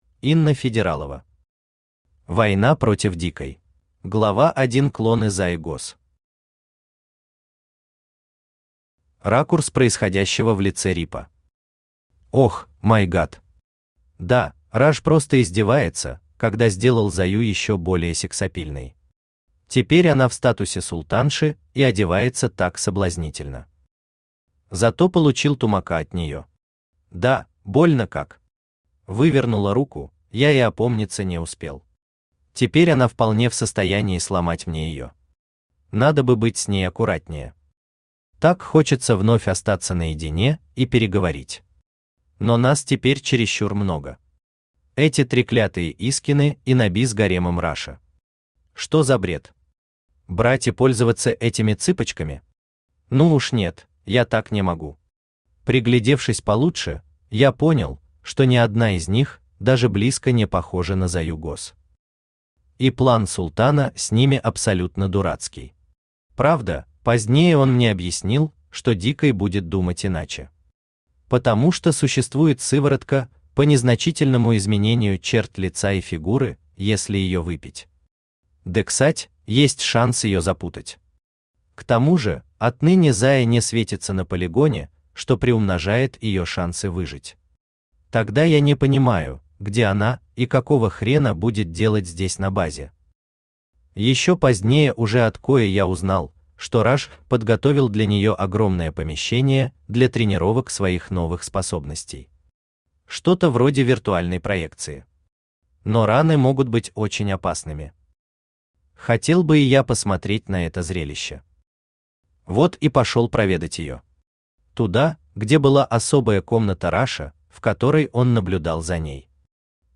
Аудиокнига Война против Дикай | Библиотека аудиокниг
Aудиокнига Война против Дикай Автор Инна Федералова Читает аудиокнигу Авточтец ЛитРес.